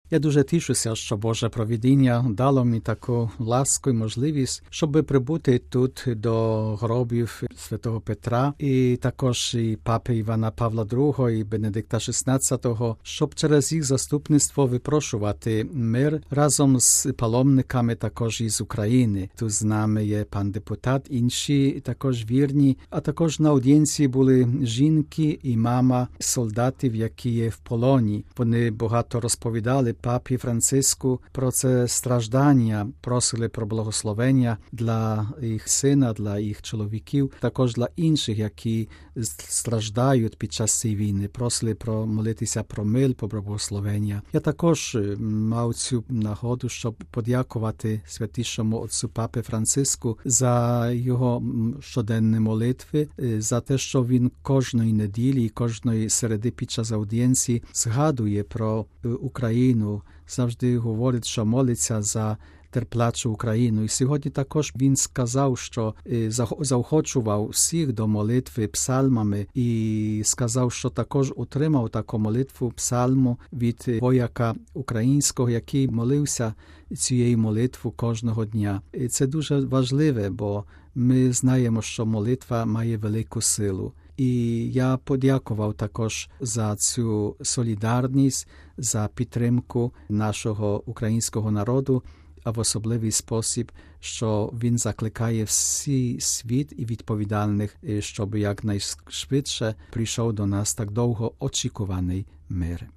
Після загальної аудієнції він поділився деякими думками з нашою редакцією: